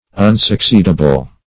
Search Result for " unsucceedable" : The Collaborative International Dictionary of English v.0.48: Unsucceedable \Un`suc*ceed"a*ble\, a. Not able or likely to succeed.
unsucceedable.mp3